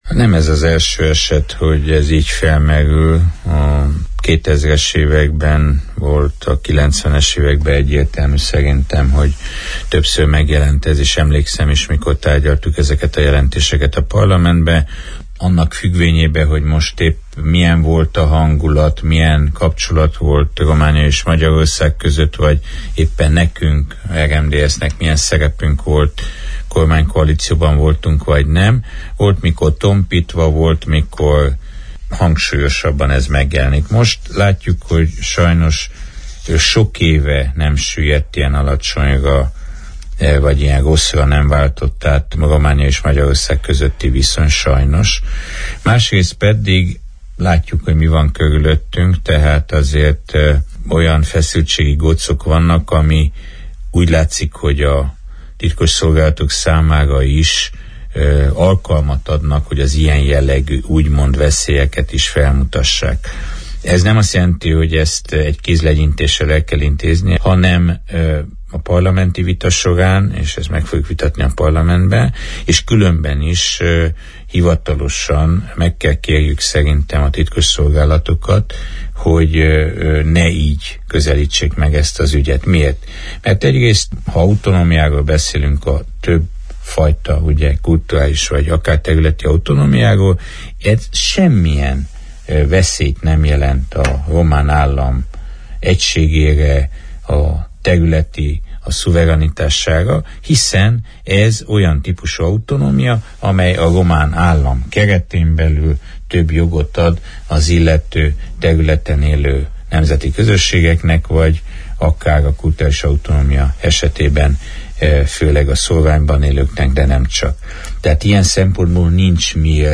A kérdéssel kapcsolatosan Borbély László Maros megyei parlamenti képviselő a szerda délutáni Naprakész műsorban fejtette ki véleményét, az RMDSZ álláspontját: